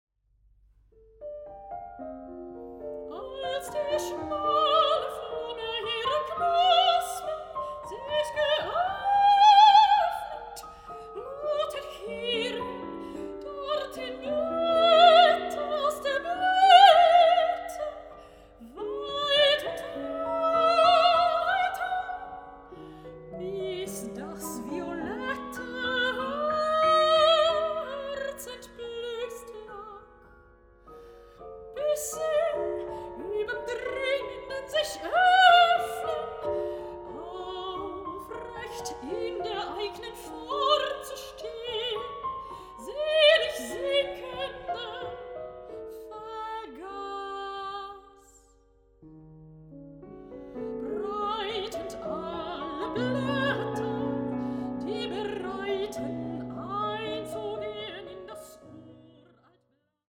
Mezzo-soprano
piano
Recording: Mendelssohn-Saal, Gewandhaus Leipzig, 2025